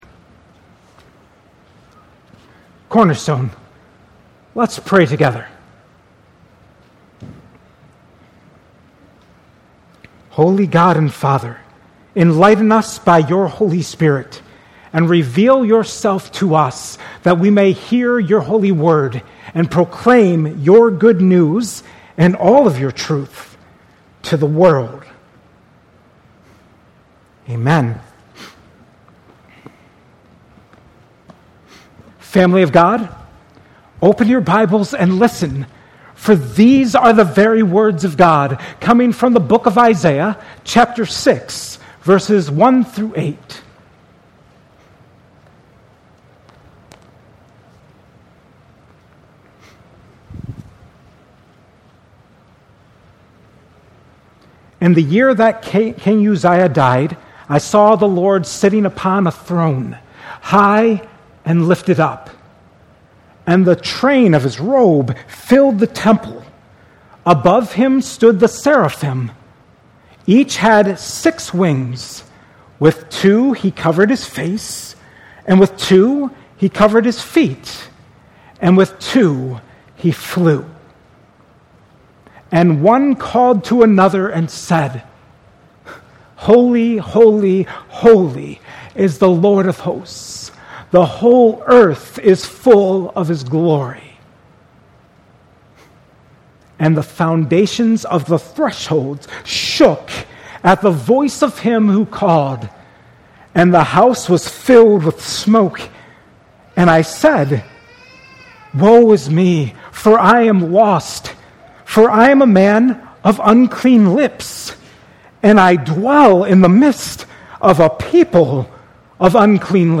2025 at Cornerstone Church.